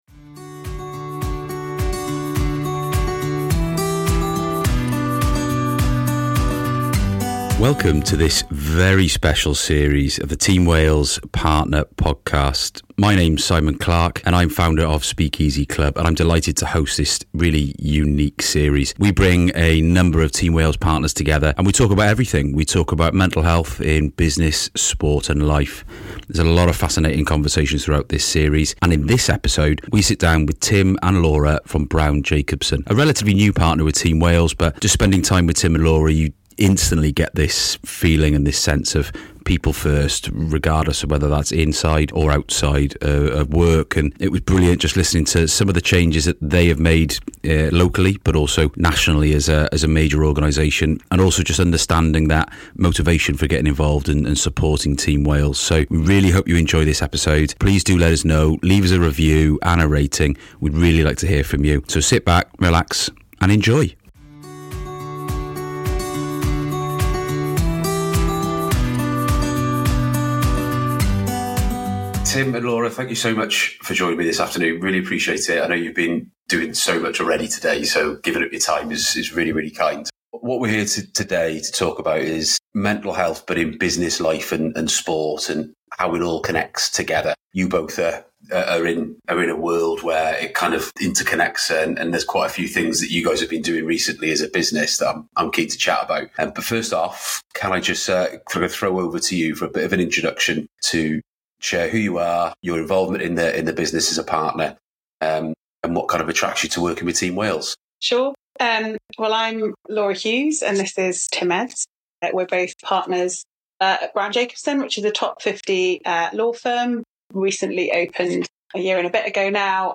In this series, we’re joined by Team Wales partners and some very special guests for an open and honest conversation about mental health in business, sport and life. We’ll explore how these organizations support their teams, the reasons behind their commitment to mental well-being, and how to navigate those tough but essential conversations.